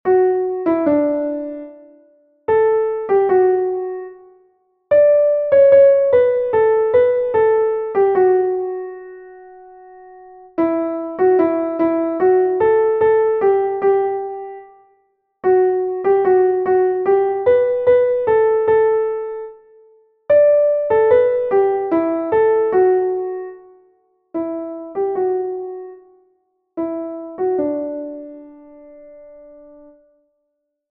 das Wiegenlied - ukolébavka
Protože jsem bohužel neobjevil žádný vokální ani instrumentální záznam písně, přepsal jsem nalezené notové zánamy a z nich vytvořil zvukové záznamy melodie.
Tříčtvrteční varianta, kde je autorem hudby Louis Spohr.